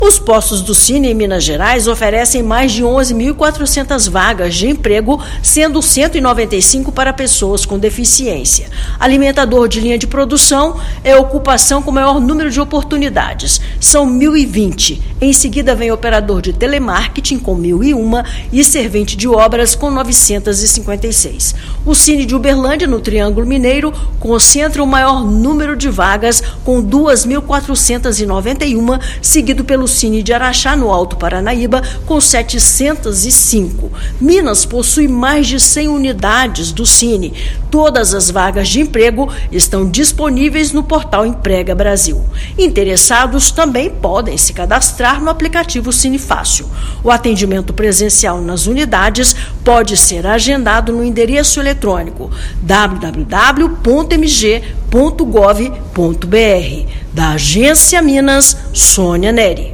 Oportunidades por município e ocupações podem ser conferidas pela internet no Painel de Informações sobre o sistema. Ouça matéria de rádio.